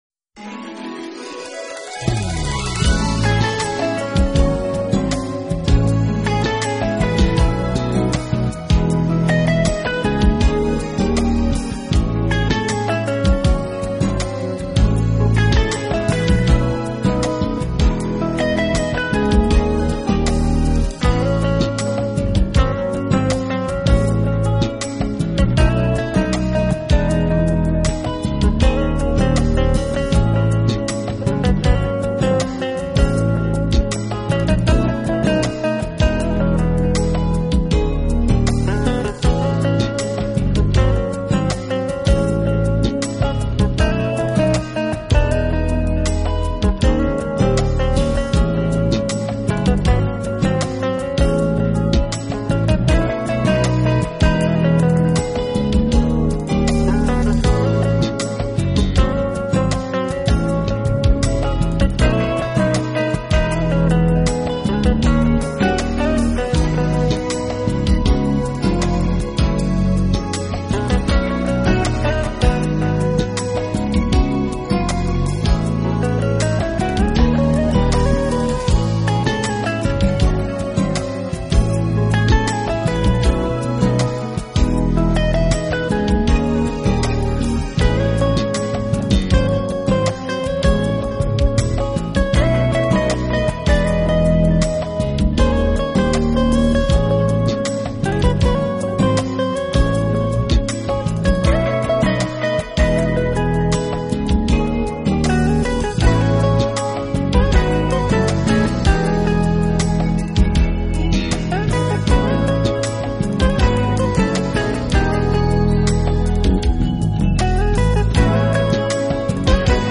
【爵士吉他】
音乐类型：Jazz
简洁、利落，并配以少量的弦乐伴奏，在作品中时而会有著名歌手和乐手的加盟。